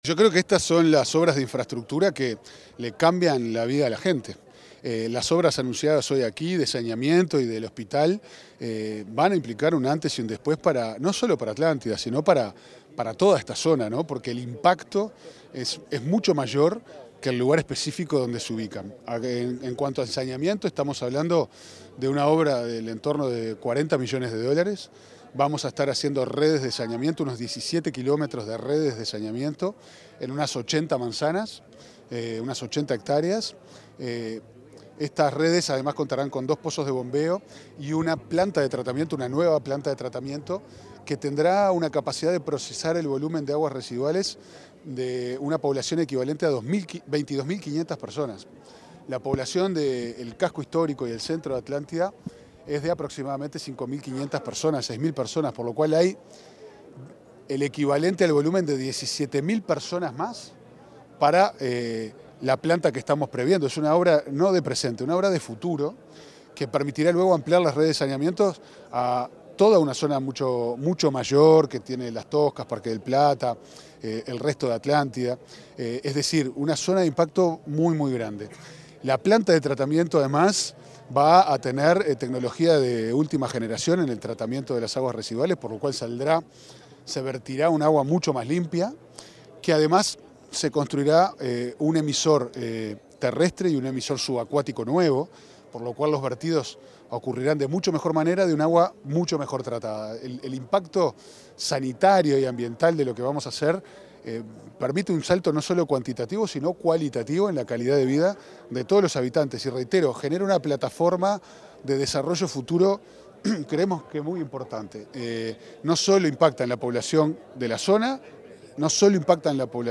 Declaraciones del presidente de OSE, Pablo Ferreri